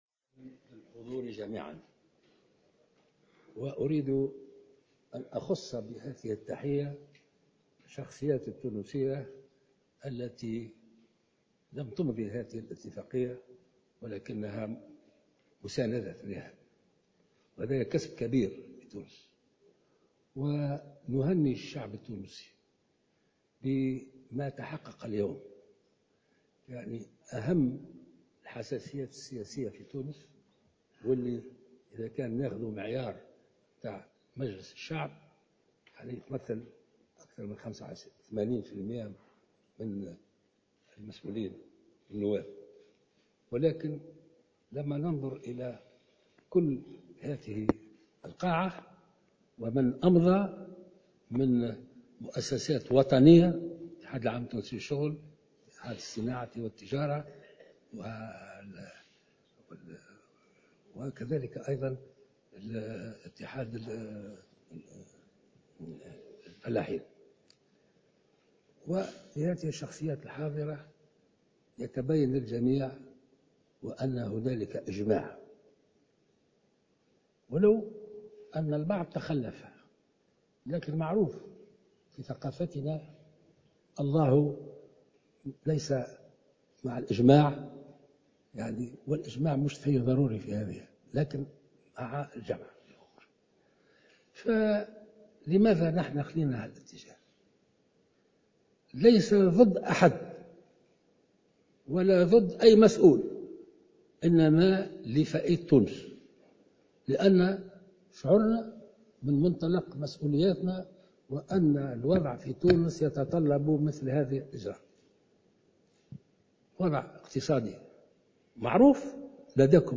أكد رئيس الجمهورية في كلمة توجه بها خلال الموكب الرسمي الذي انتظم صباح اليوم الأربعاء بقصر قرطاج للتوقيع على "وثيقة قرطاج"، أن الاتحاد العام التونسي للشغل كان من بين الموقّعين على هذه الوثيقة بالإضافة إلى منظمة الأعراف والاتحاد التونسي للفلاحة والصيد البحري.